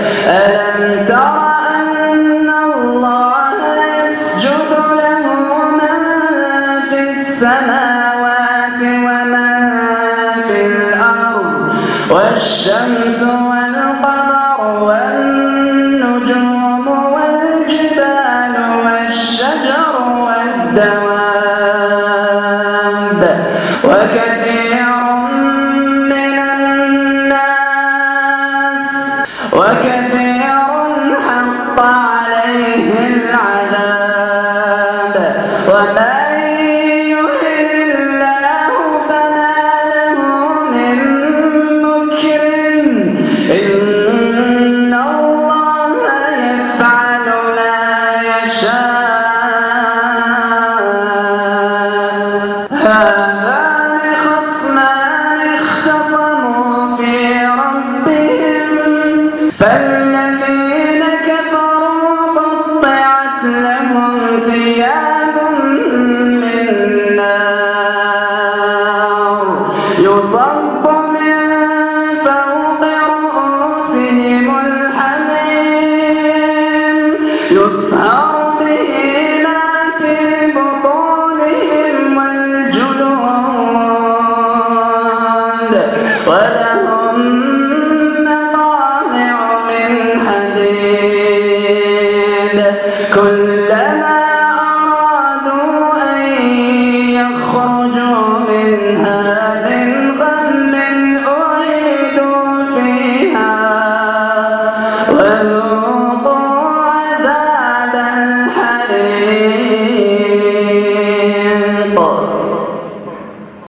تلآوة خاشعة